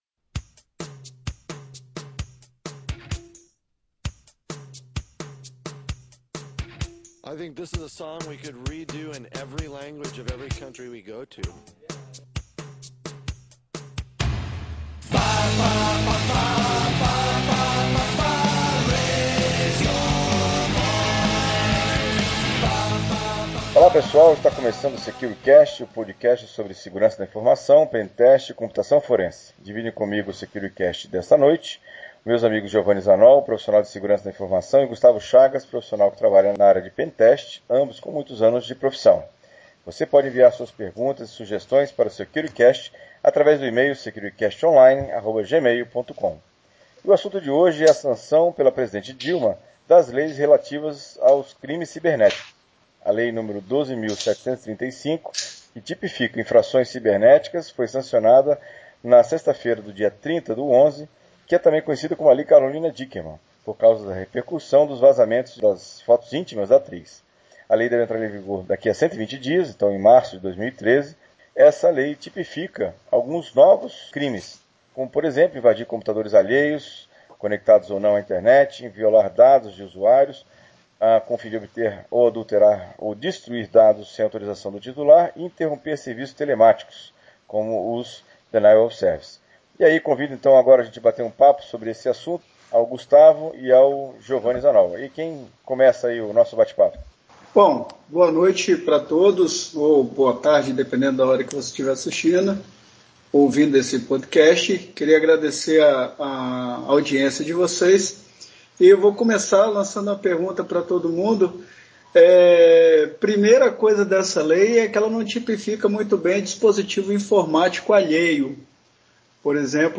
conversam sobre a sanção feita pela Presidenta Dilma das leis relativas aos crimes cibernéticos. O que isto muda para os internautas? Quais cuidados devem ser tomados?